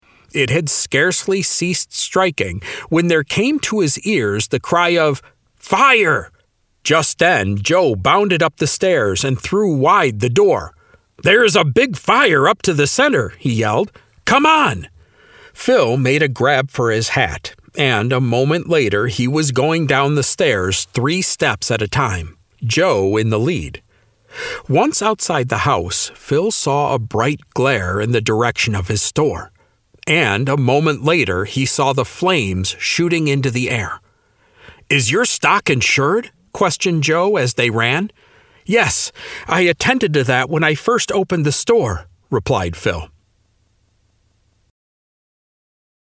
Audiobook: Phil Tyler's Stand - MP3 download - Lamplighter Ministries